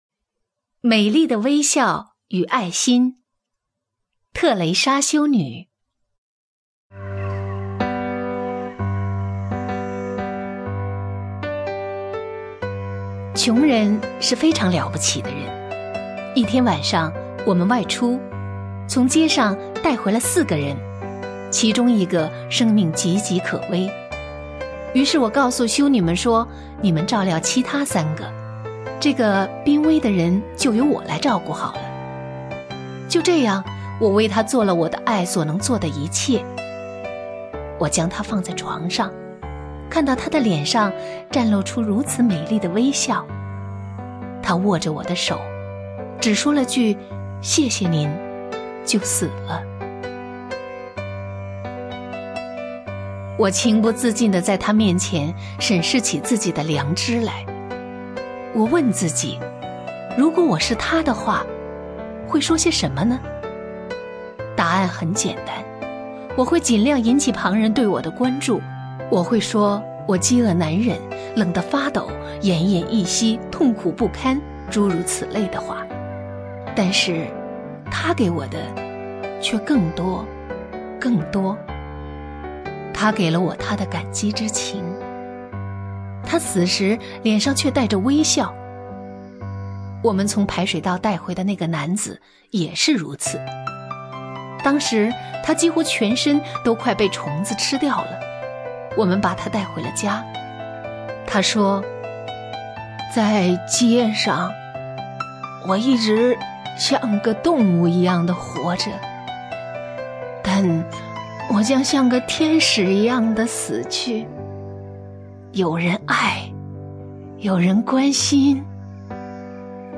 首页 视听 名家朗诵欣赏 欧阳婷
欧阳婷朗诵：《美丽的微笑与爱心》(（印度）特蕾莎修女)　/ （印度）特蕾莎修女